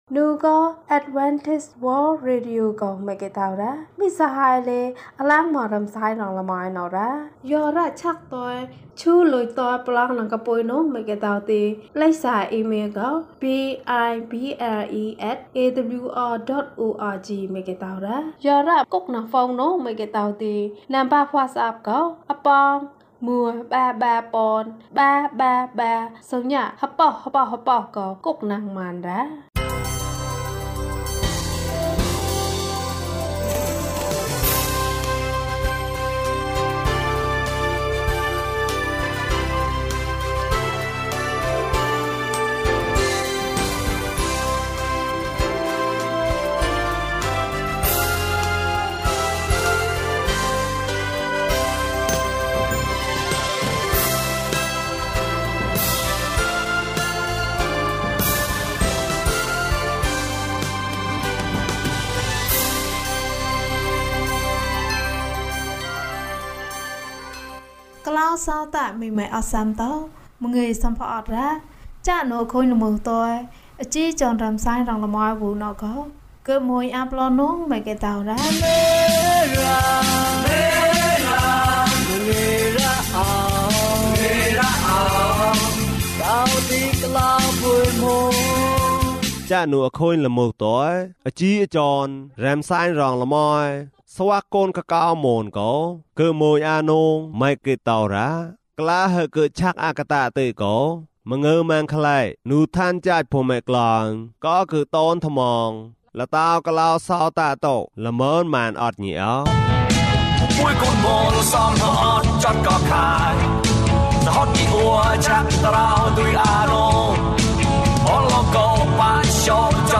ငါ့ဆုတောင်းသံကို ဘုရားသခင် နားထောင်ပါ။ အပိုင်း ၂ ကျန်းမာခြင်းအကြောင်းအရာ။ ဓမ္မသီချင်း။ တရားဒေသနာ။